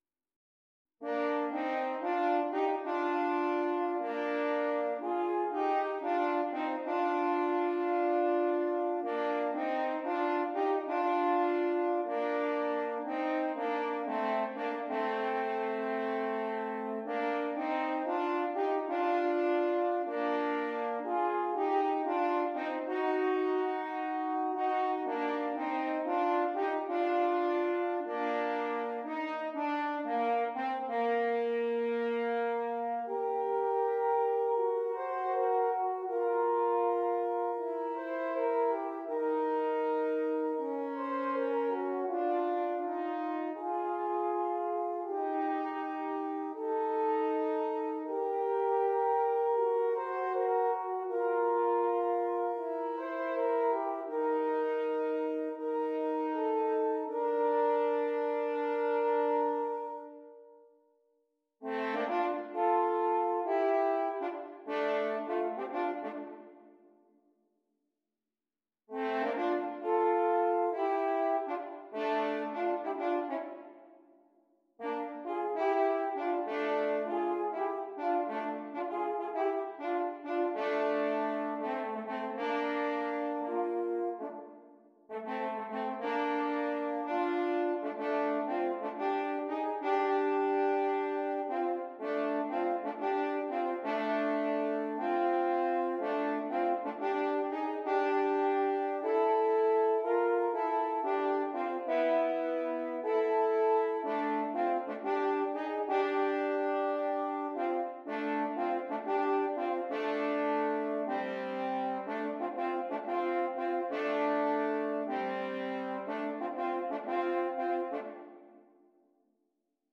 2 F Horns
A varied assortment of styles is offered for your merriment.